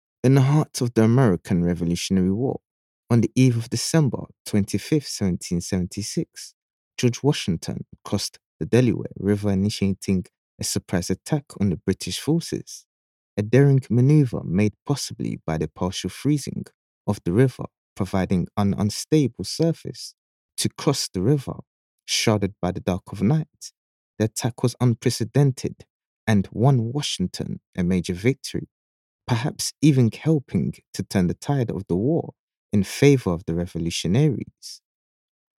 Voice Actors for Training and Instructional Narration
English (Caribbean)
Yng Adult (18-29) | Adult (30-50)